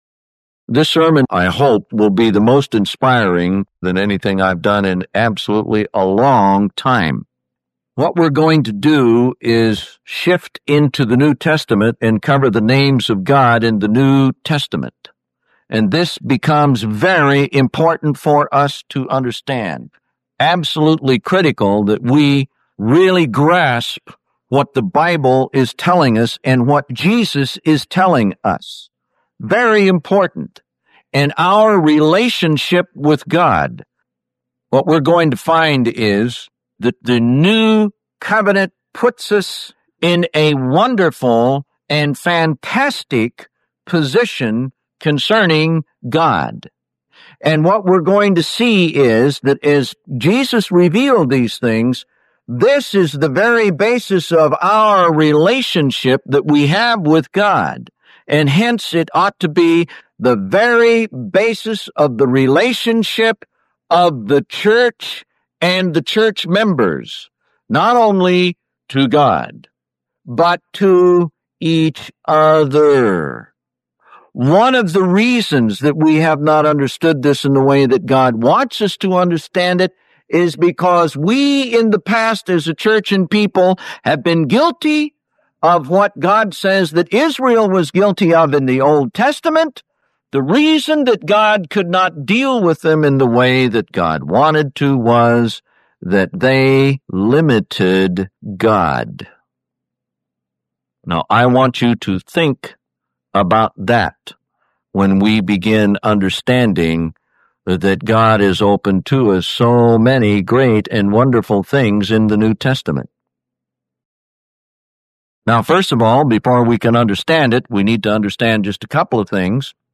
Track 2 | Download | PDF - [ Up ] This sermon, I hope, will be the most inspiring than anything I’ve done in absolutely a long time. What we’re going to do is shift into the New Testament and cover the names of God.